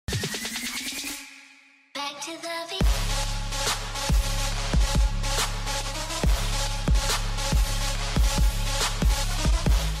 twitchhitboxyoutube-followdonation-sound-3.mp3